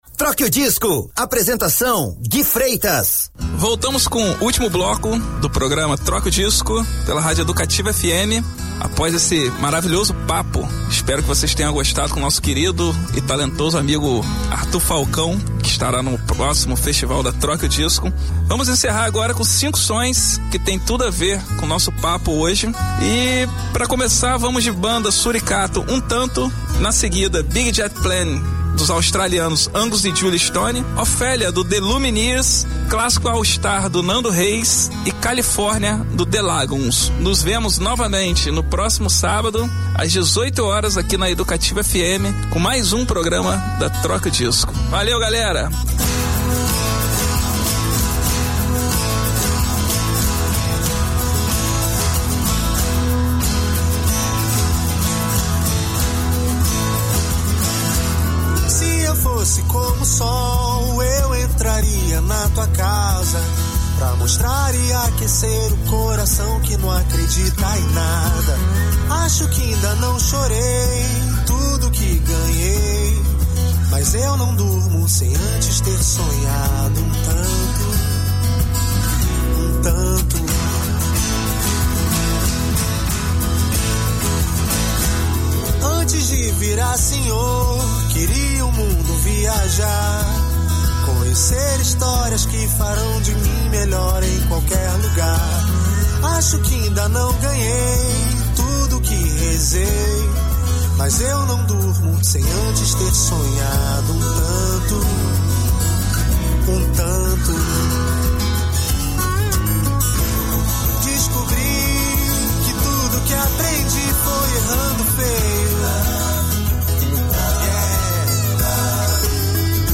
Vocês pediram e aí está, agora todos os programas Troque O Disco pela Rádio Educativa FM 107,5, estarão aqui em nosso site, vale lembrar que o programa vai ao ar todos os sábados às 18h.